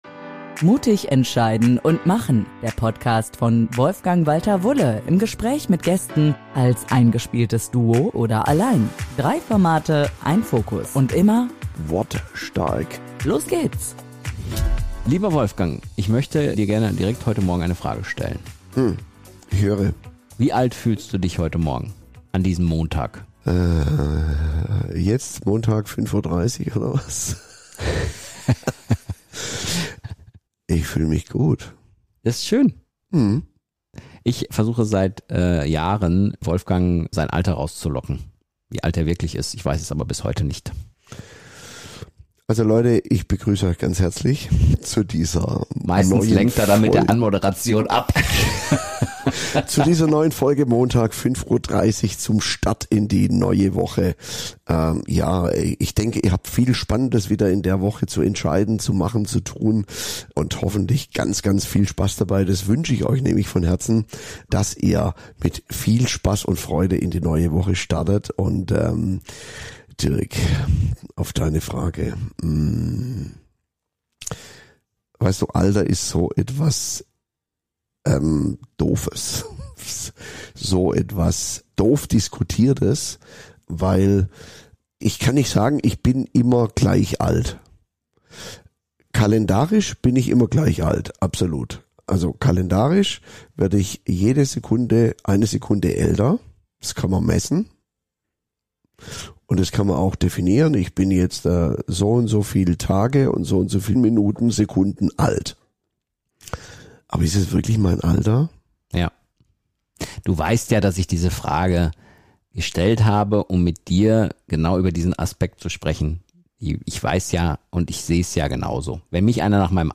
Sie zeigen, warum das kalendarische Alter oft wenig über unsere tatsächliche Energie und Lebenshaltung aussagt – und wie schnell wir durch unser Umfeld in Schubladen gesteckt werden. Es geht um den Unterschied zwischen gefühltem und tatsächlichem Alter, um gesellschaftliche Mechanismen, die Menschen „älter machen“, um Eigenverantwortung für körperliche und mentale Fitness und um die Frage, was wahre Ausstrahlung und „jugendliche“ Haltung wirklich ausmacht. Persönlich, ehrlich und mit einer Prise Humor